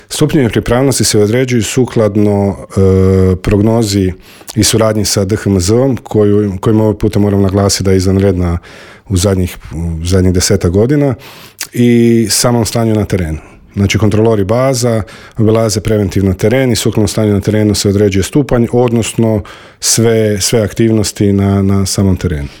Intervjuu Media servisa